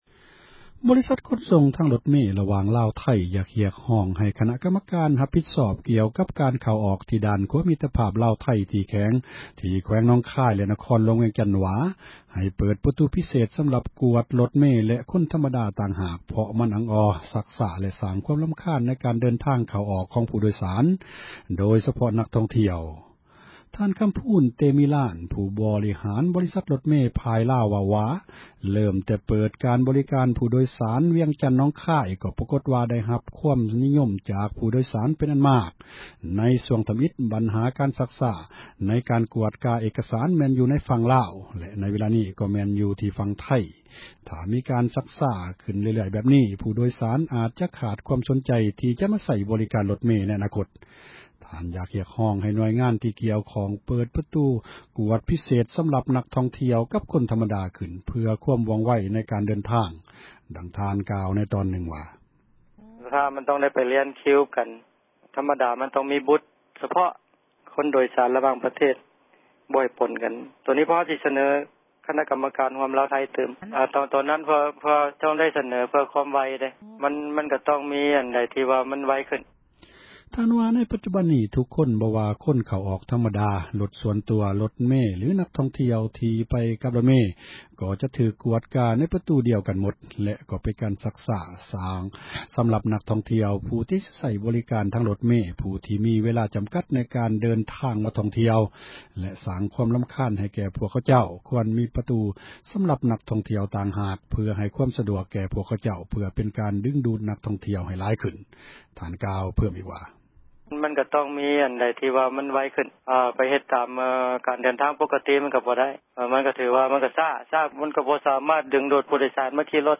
ສຽງລົດເມຊາຍແດນ